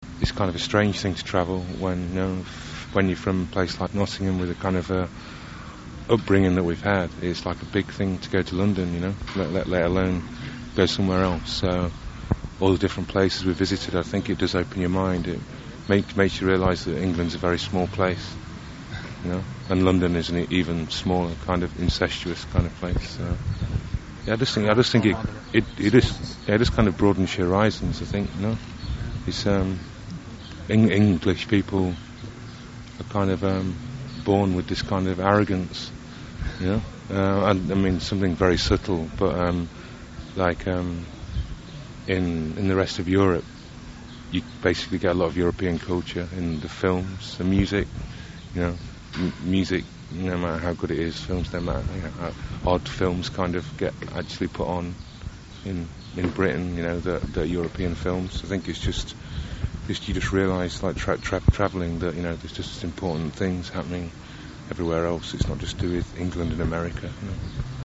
Intervju: TINDERSTICKS
Obenem so Tindersticks izdali �e dva koncertna albuma, prispevali glasbo za film franciske re�iserke Claire Denis (Nennette Et Boni) in pripravljajo tretjo redno plo��o. Pogovor s Stuartom Staplesom je nastal pred njihovim nastopom na Dunaju, 18.maja 1995.